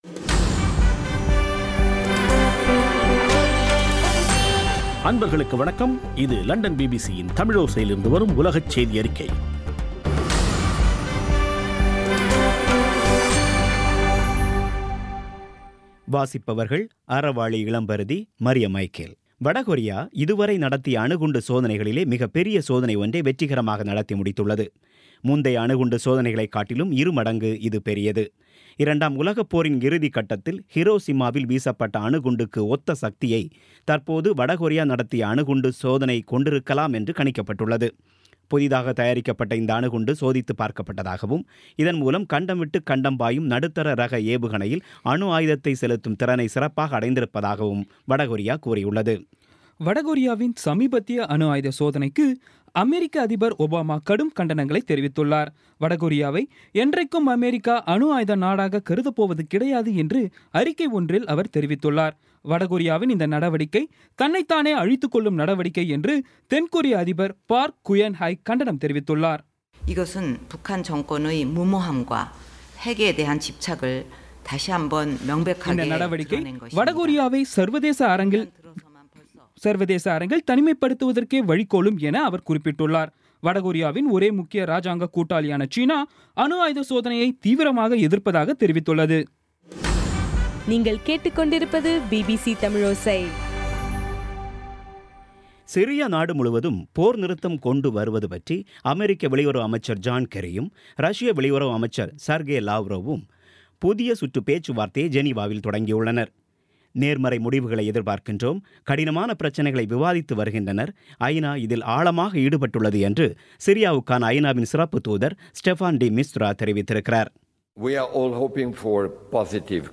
இன்றைய (ஆகஸ்ட் 9ம் தேதி ) பிபிசி தமிழோசை செய்தியறிக்கை